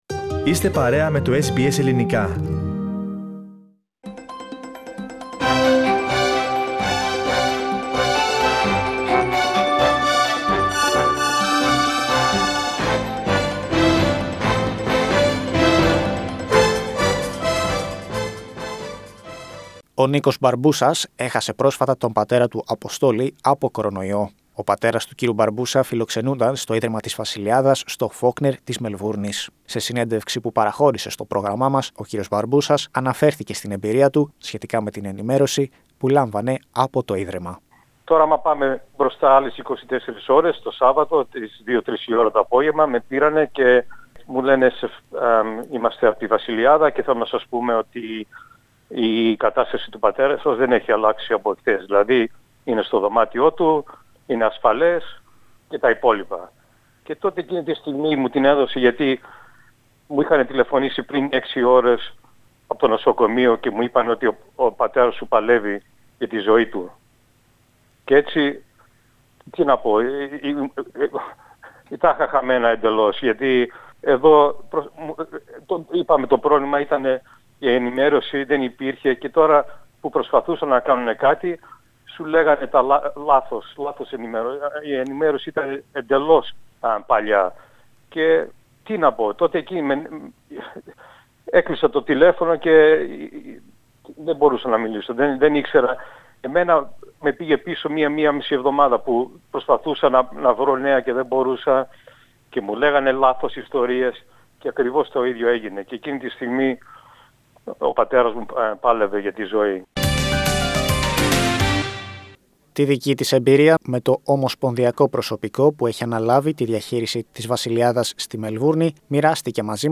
Interviews with relatives of residents in St Basil's and a free hotline for the elderly that provides support amidst the pandemic were some of the stories brought to you by SBS Greek the past week.